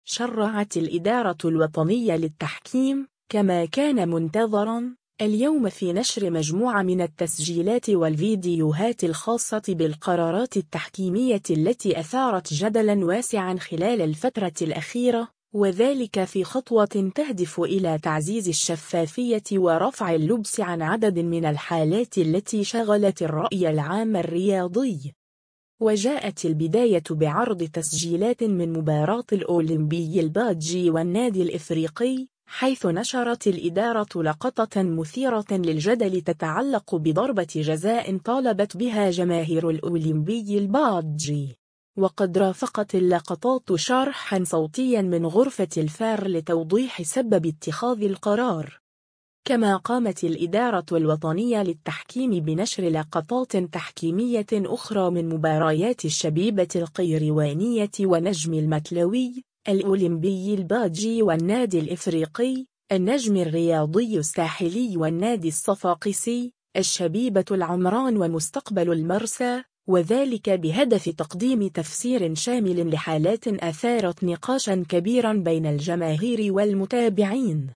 وقد رافقت اللقطات شرحًا صوتيًا من غرفة “الفار ” لتوضيح سبب اتخاذ القرار.